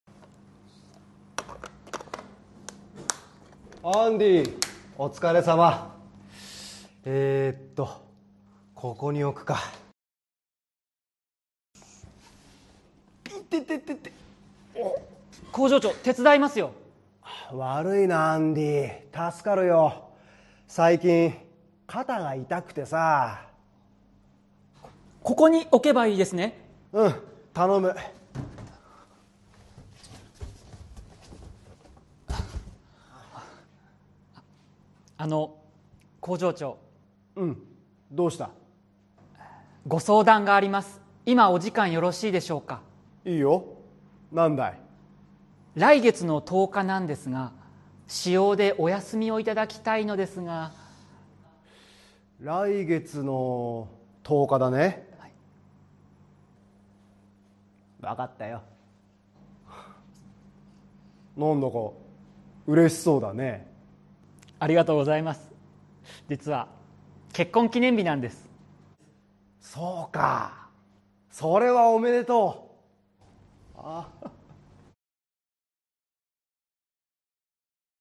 Role-play Setup